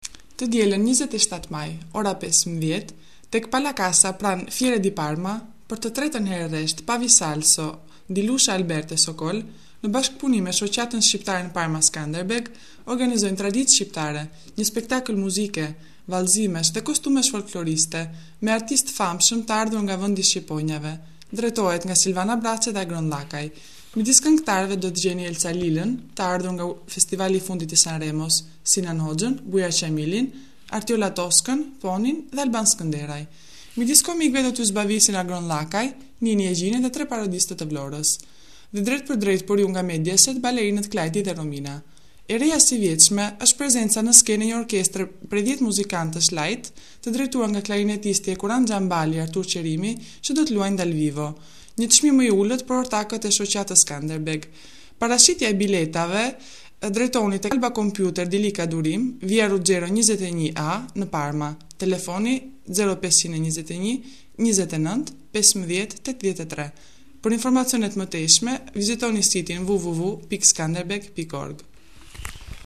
Spot Audio